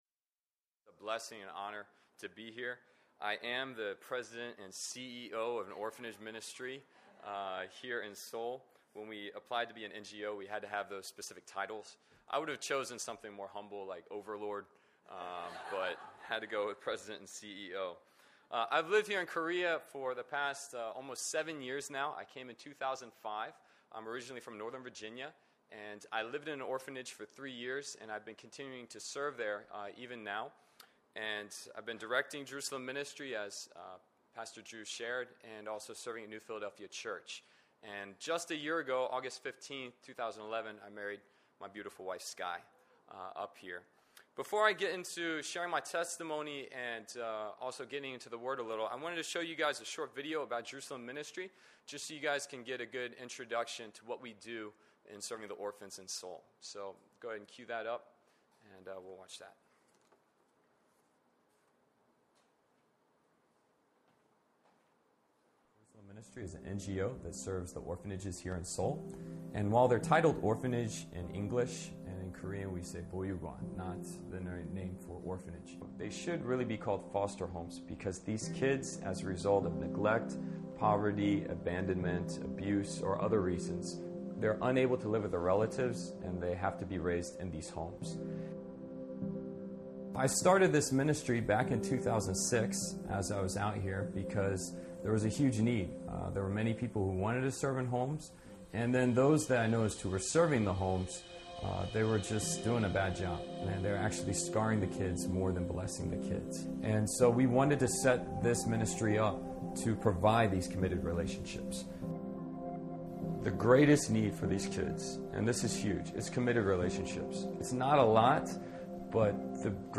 Sermon: Experiencing Breakthrough in Life